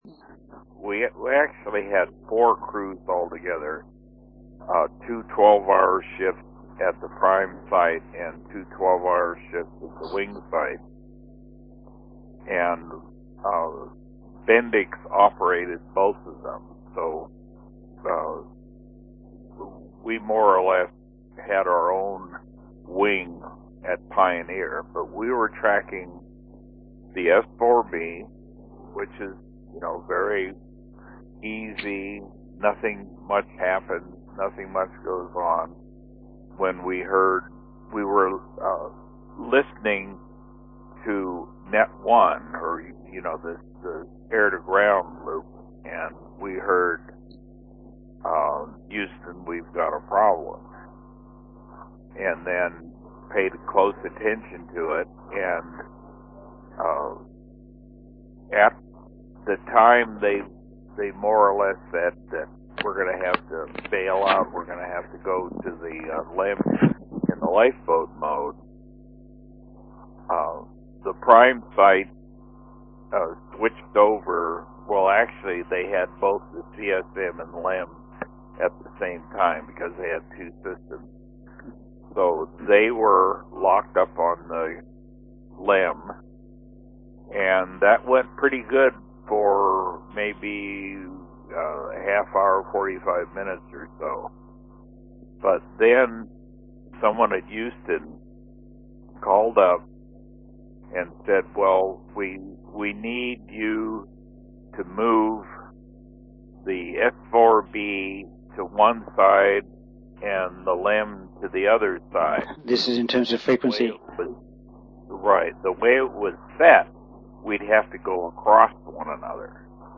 Apollo 13 interviews